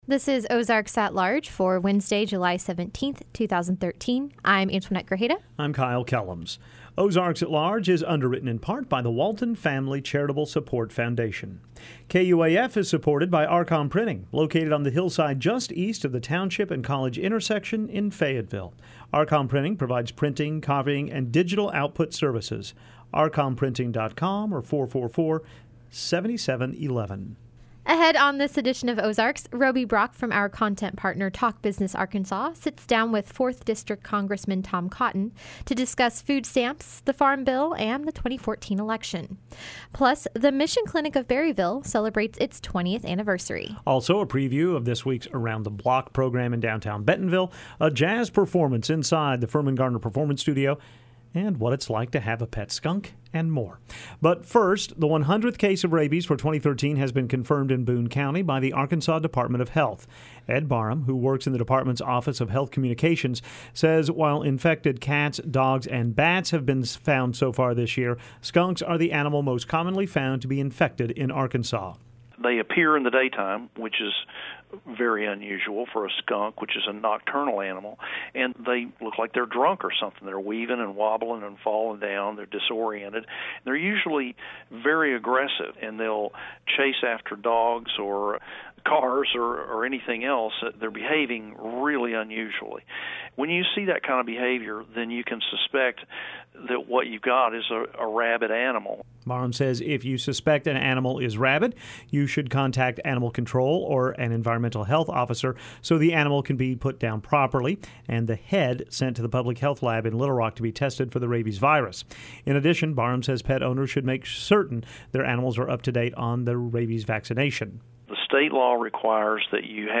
On this edition of Ozarks, a conversation with Congressman Tom Cotton and the Mission Clinic of Berryville celebrates its 20th anniversary.